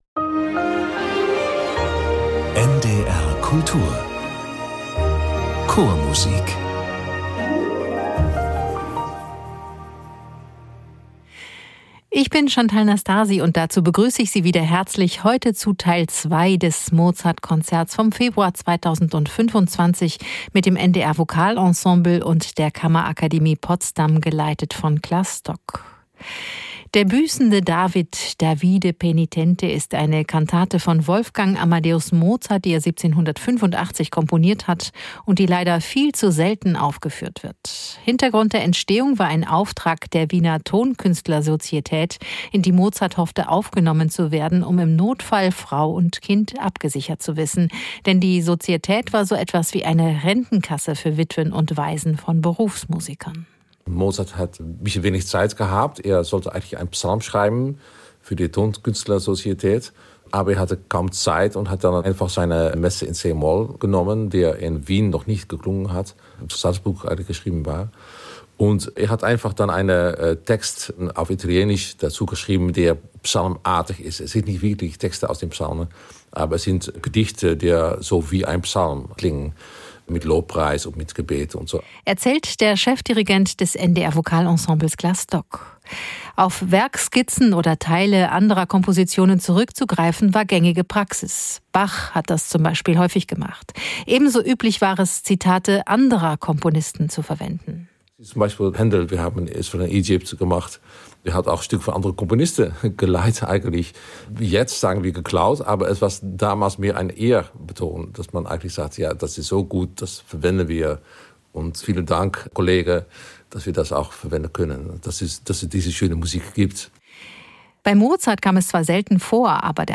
Ein reiner Mozart-Abend mit dem NDR Vokalensemble Teil II ~ Chormusik - Klangwelten der Vokalmusik entdecken Podcast
Mozarts kaum bekannte Kantate "Davide Penitente" basiert auf seiner Messe c-Moll, ergänzt um etliche Koloraturpassagen.